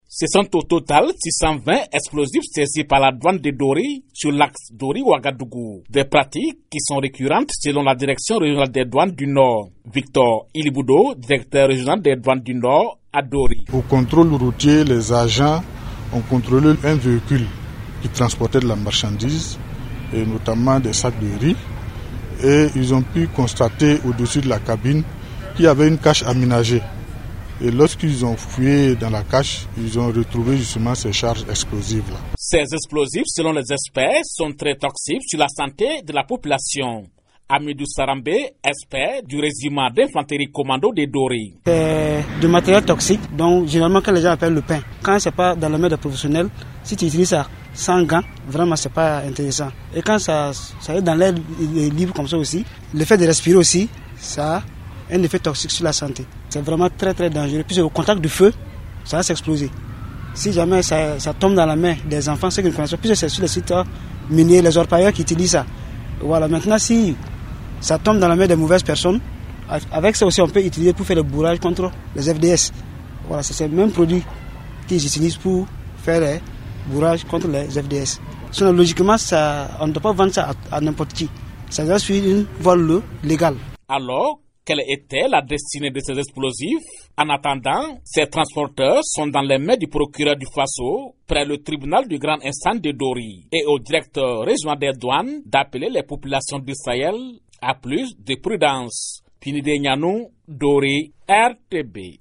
element-dori-des-charges-explosives-saisies-par-la-douane-de-dori.mp3